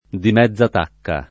[ di m $zz a t # kka ]